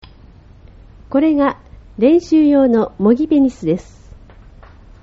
音声による説明 　これが、練習用の模擬ペニスです。